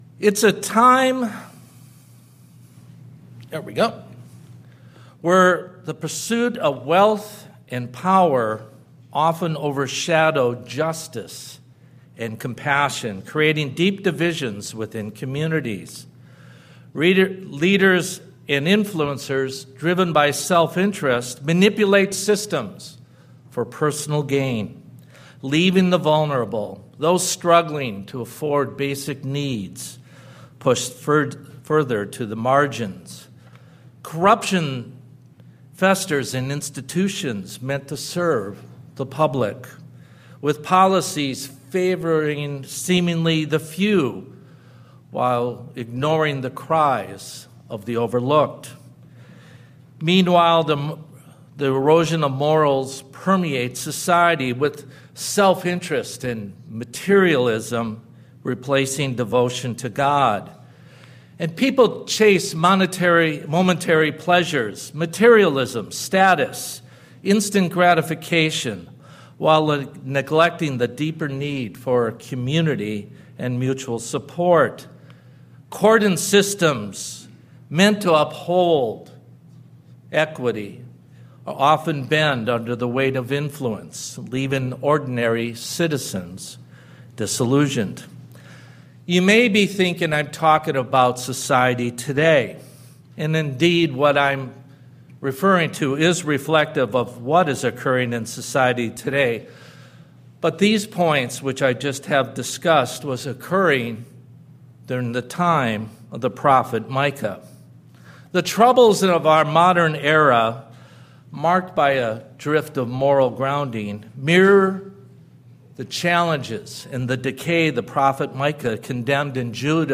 Sermons
Given in Spokane, WA Kennewick, WA Chewelah, WA